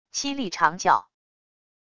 凄厉长叫wav音频